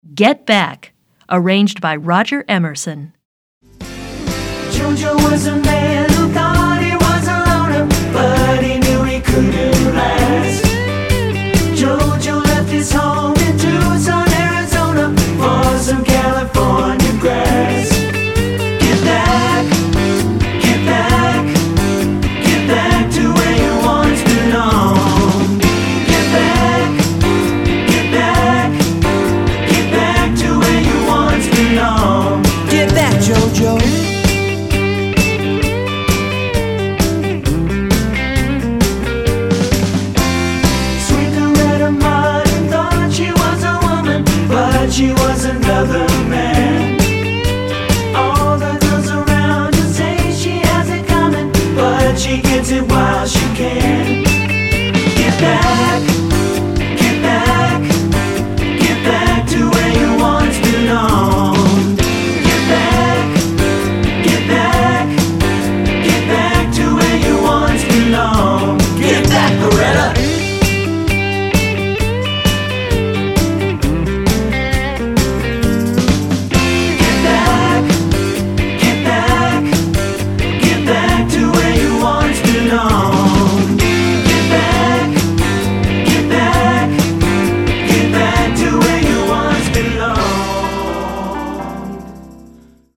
Voicing: TB